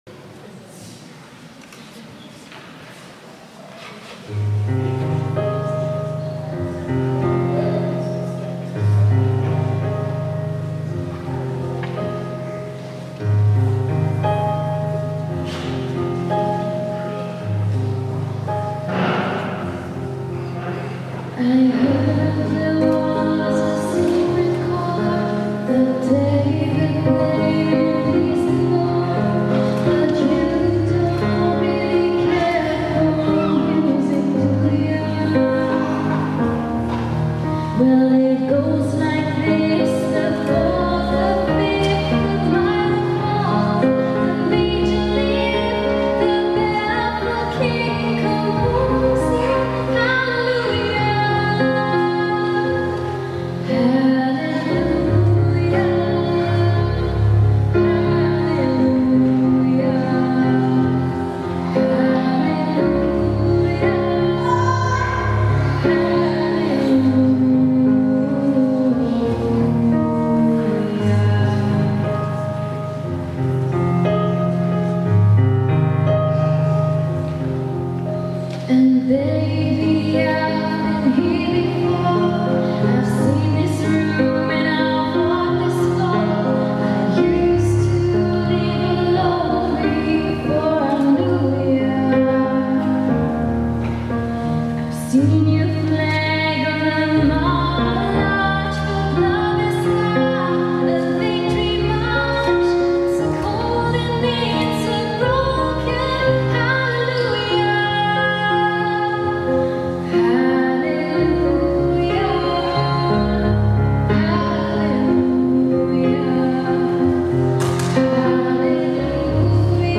Singer Songwriter Pop